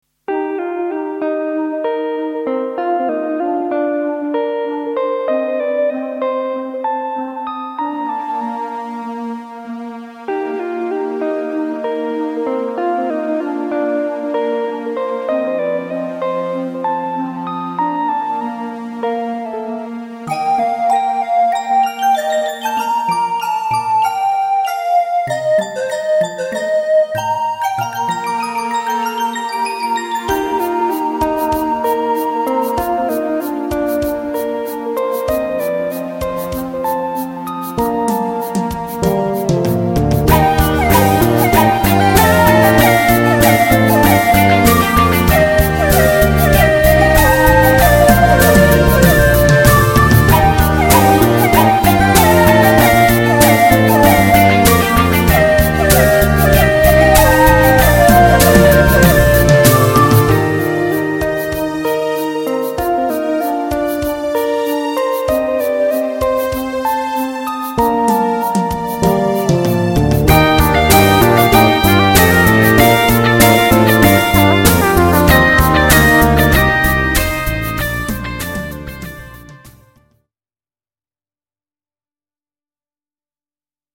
Afwisselend berustend en opwekkend.